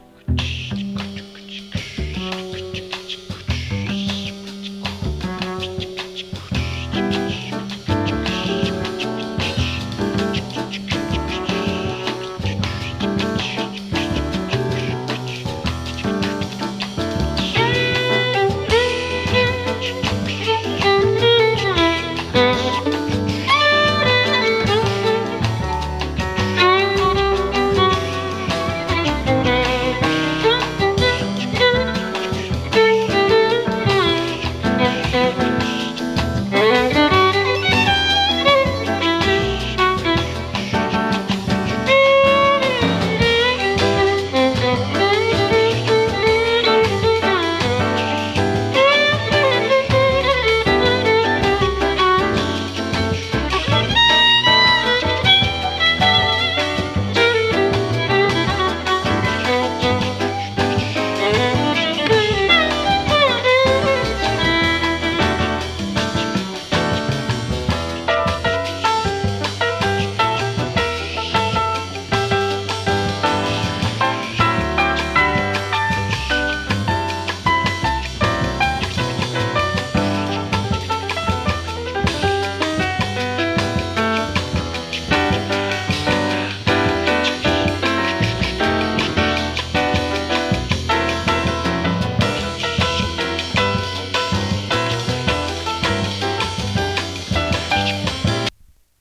アコースティック スウィング